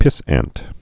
(pĭsănt) Slang